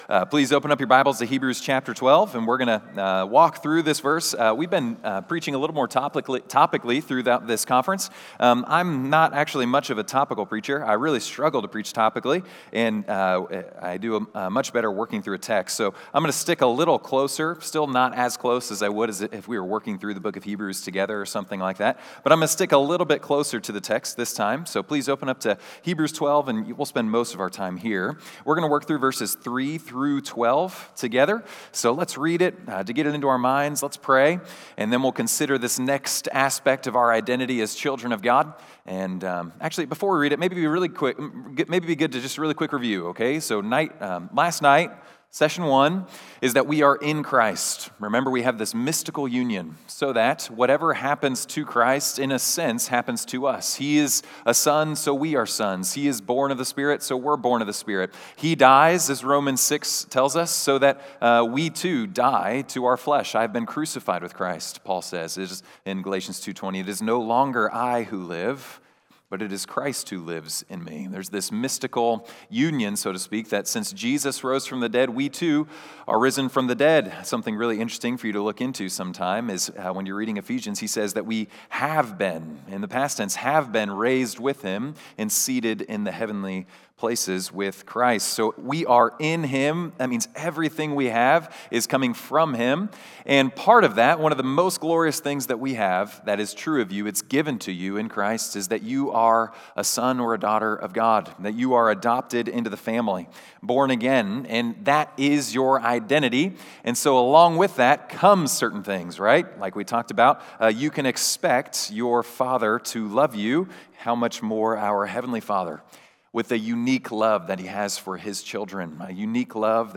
Winter Retreat 2025 – In Christ: Being before Doing An exploration into our identity we receive in Christ and how that practically affects our lives.